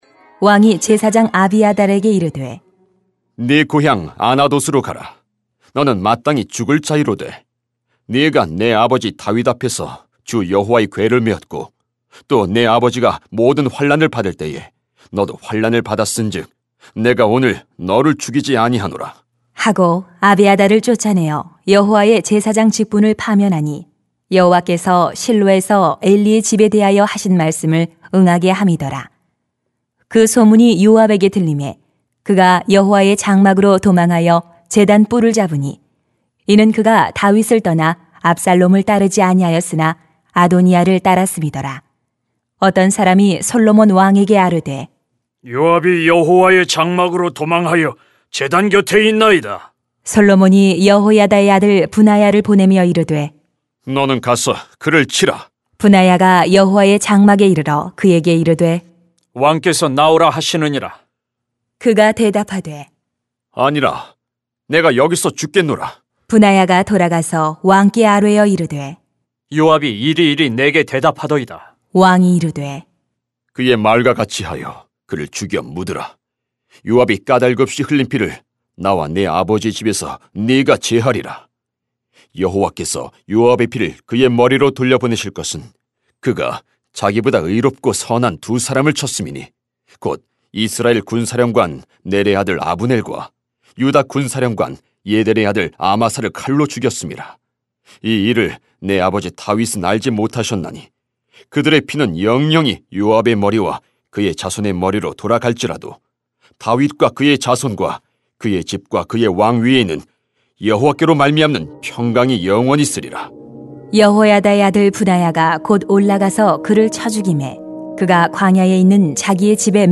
[왕상 2:26-35] 하나님 나라의 걸림돌이 되지 맙시다 > 새벽기도회 | 전주제자교회